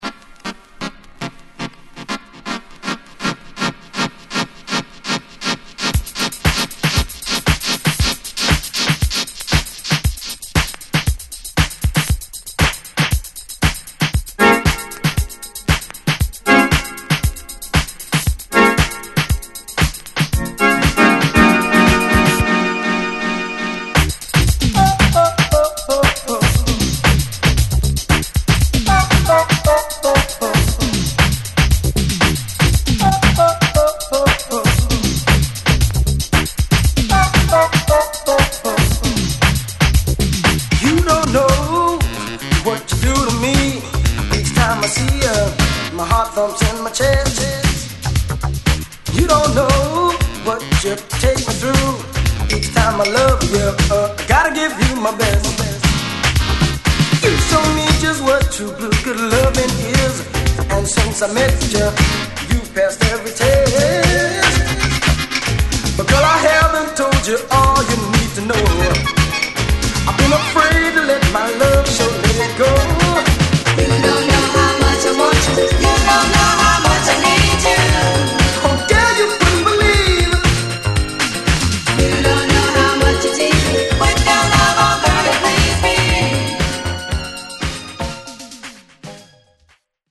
・ 45's HOUSE / TECHNO